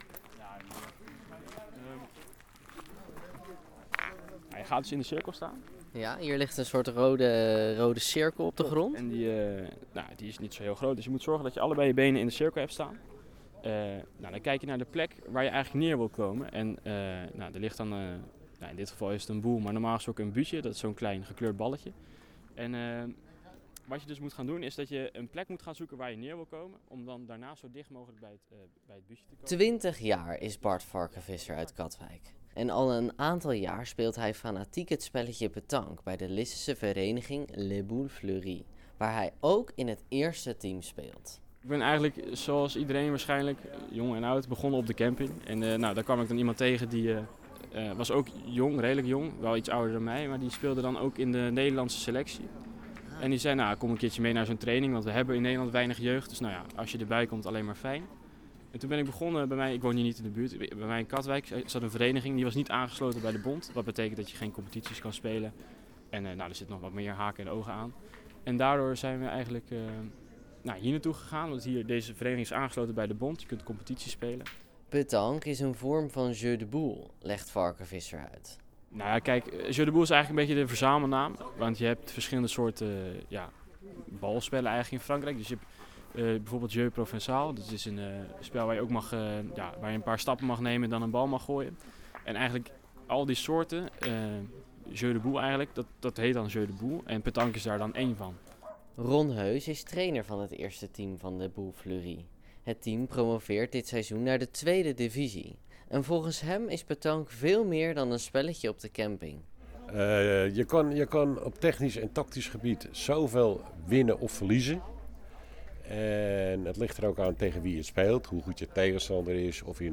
Lisse –  Metalen ballen ketsen zaterdagochtend tegen elkaar op het terrein van Les Boules Fleuries. Vanwege Nationale Petanquedag openden in het hele land verenigingen hun deuren voor mensen om kennis te maken met het spelletje.